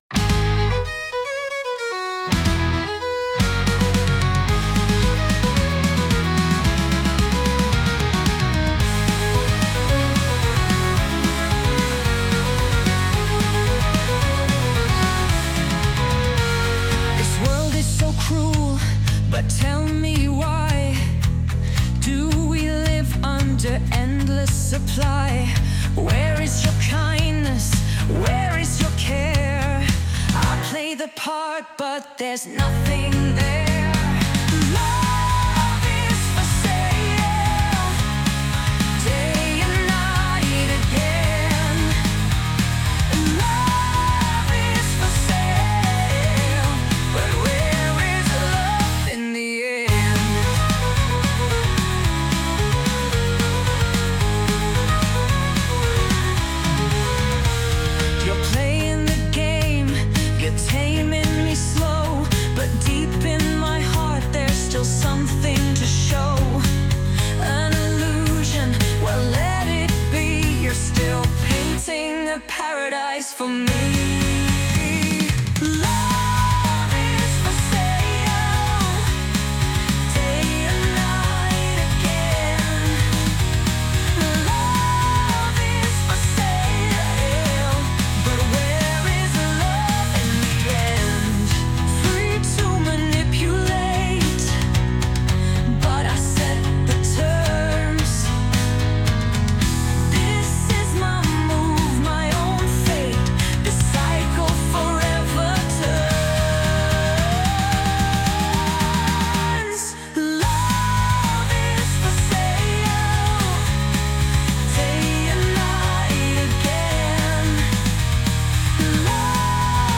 English, Romantic, Rock, Blues, Soul | 18.03.2025 19:55